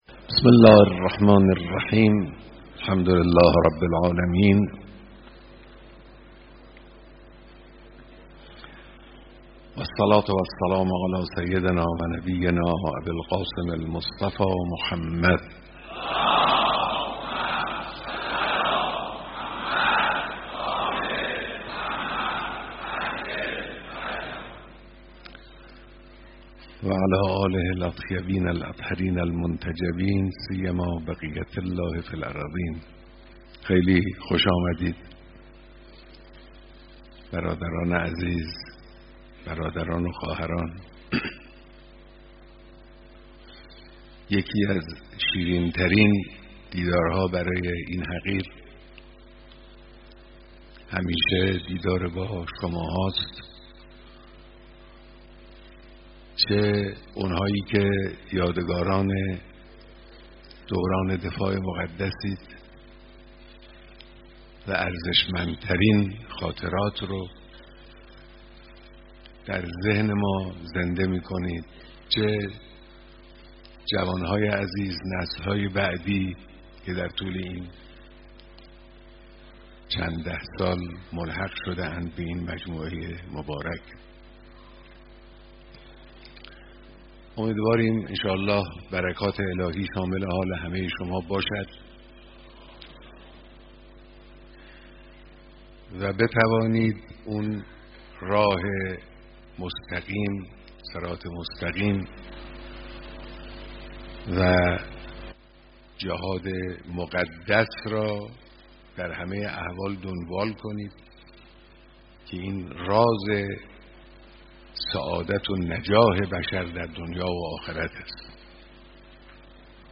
بیانات رهبر انقلاب در دیدار فرماندهان سپاه پاسداران